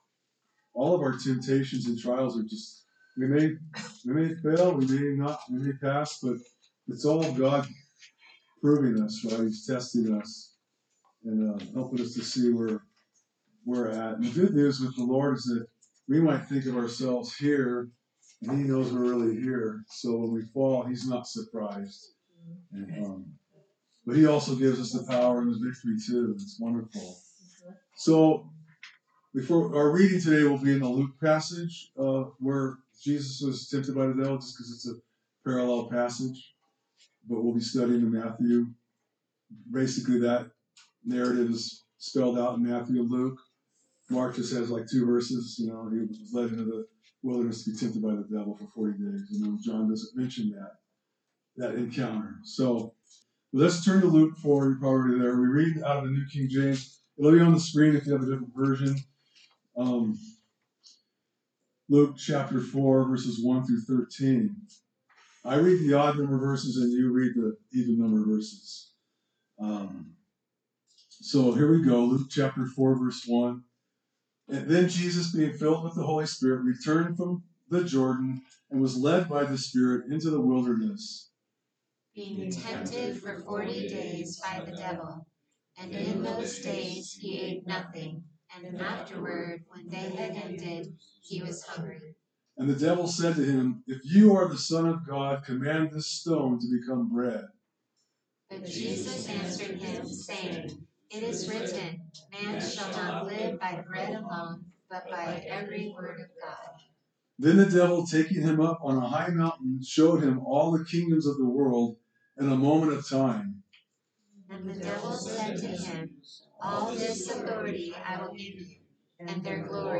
A message from the series "Matthew."